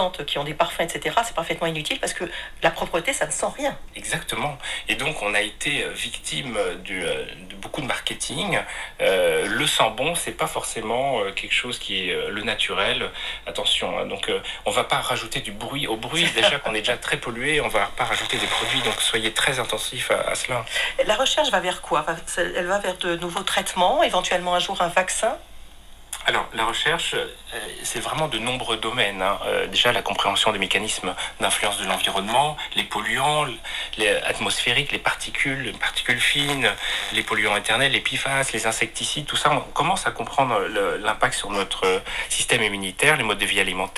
Here's a recording I just made of a random radio show playing on a small FM radio. I recorded it with Sonic Presence binaural microphones: SP15C – Binaural USB Mic (USB-C) Product Overview: The SonicPresence® SP15C (USB-C) is a new binaural microphone that records amazing Spatial Audio on Mobile and Desktop devices.
The radio moves in the horizontal plane - that can be heard clearly with headphones - then in the vertical plane and front and back - differences are subtle, with a single point source, perhaps the differences would be more significant with two sources, one fixed and the other moving around.
This is a crude experiment.
binaural recording.wav